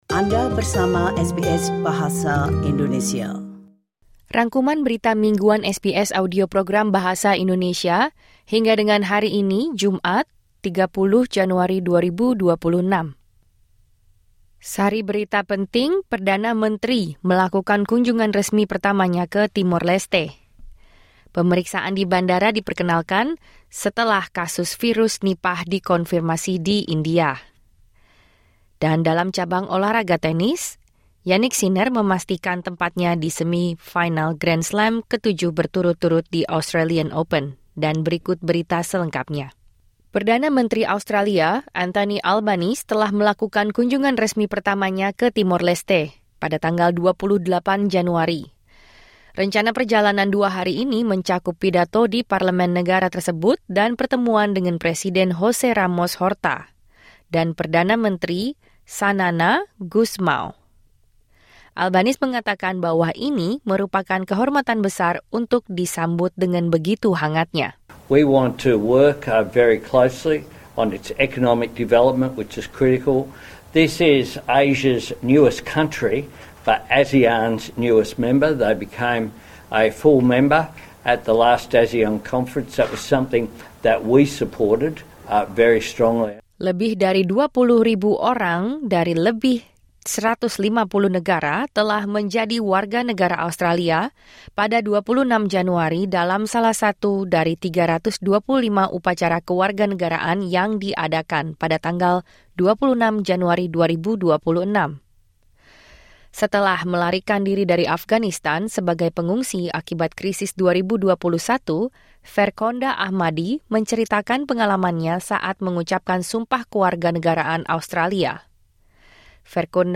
Rangkuman Berita Mingguan SBS Audio Program Bahasa Indonesia - Jumat 30 Januari 2026